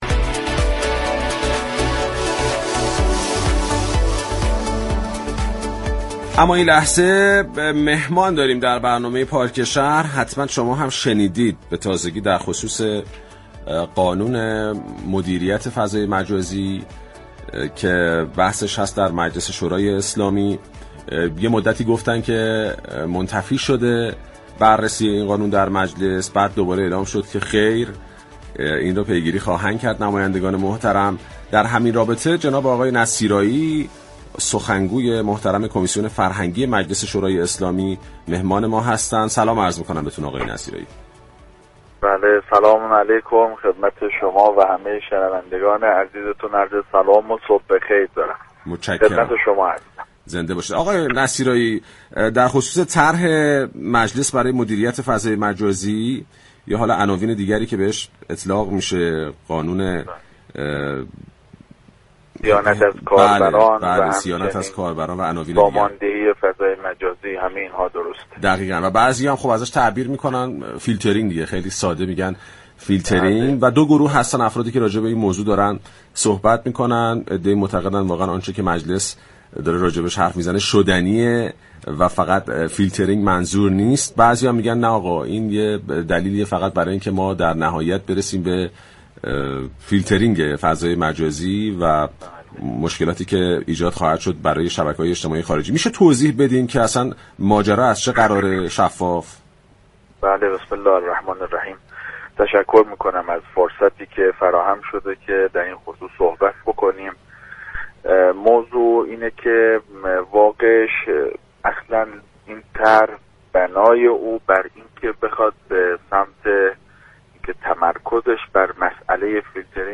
به گزارش پایگاه اطلاع رسانی رادیو تهران، حجت الاسلام مجید نصیرایی سخنگوی كمیسیون فرهنگی مجلس شورای اسلامی در گفتگو با پارك شهر رادیو تهران گفت: به صراحت اعلام می‌كنم كه در این طرح، بنای ما فیلتر كردن نیست.